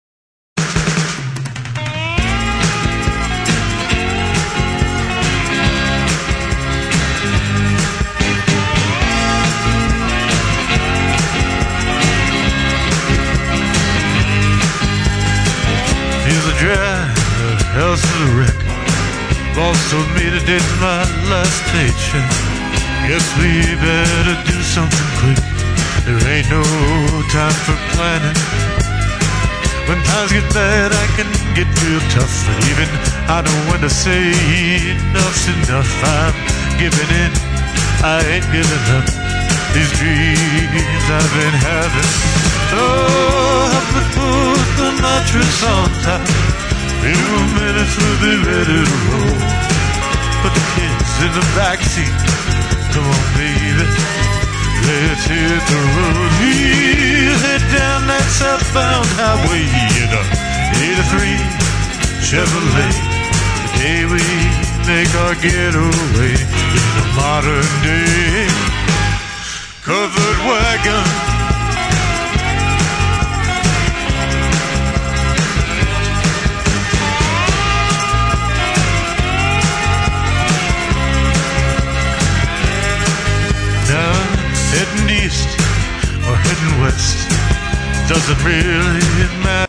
mp3 / S / Alt Country